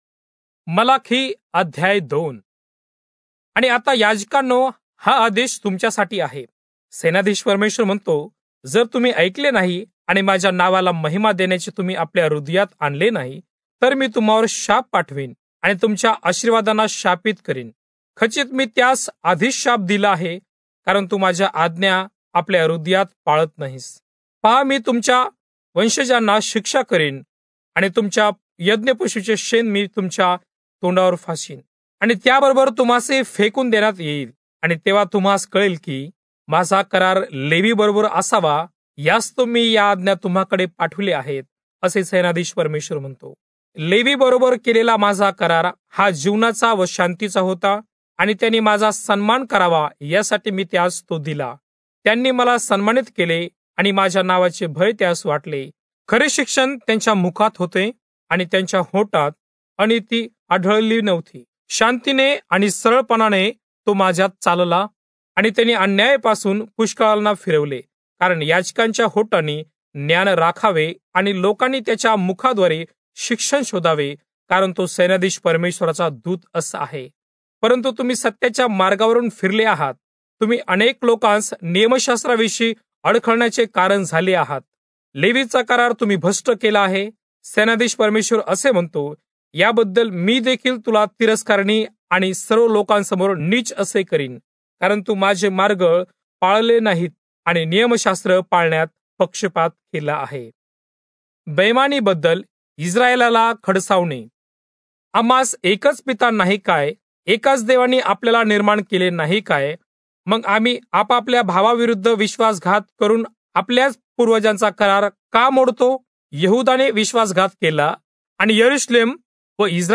Marathi Audio Bible - Malachi 3 in Irvmr bible version